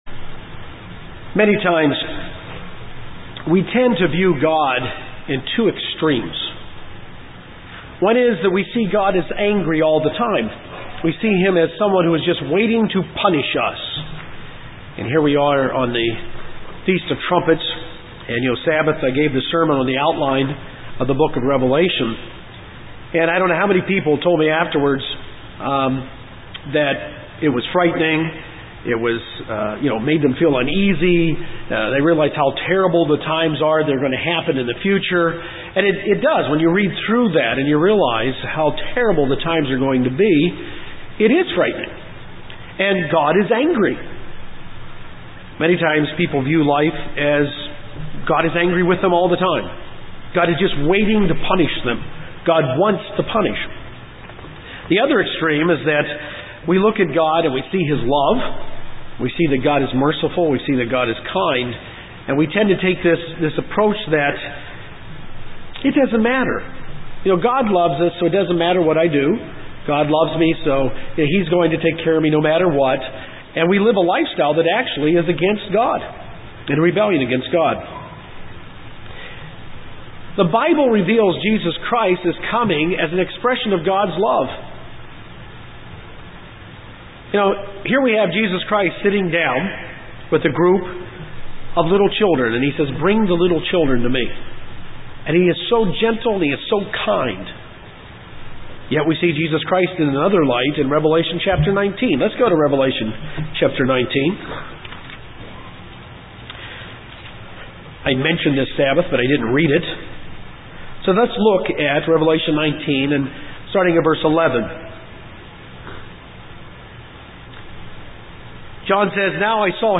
In this sermon, given on the Feast of Trumpets, we will learn about the wrath of God that is to come and see a picture of His love for mankind.